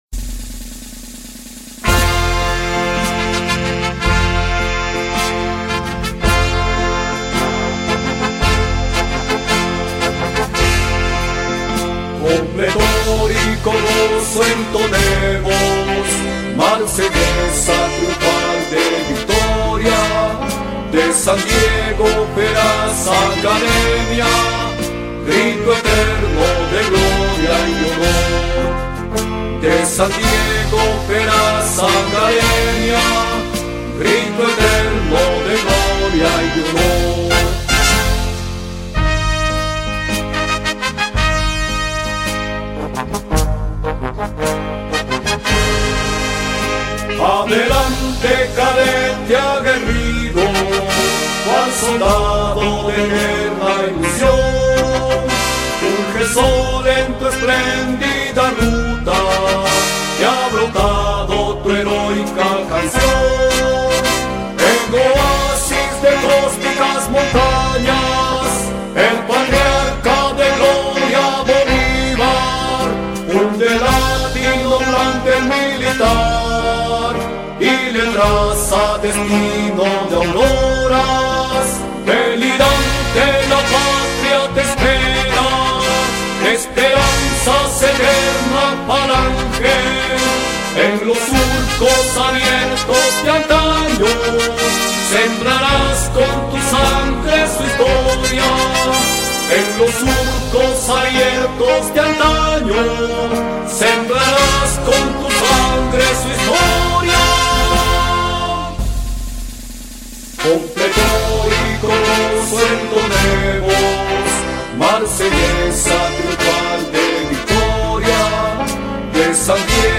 Himno-ACMIL-San-Diego.mp3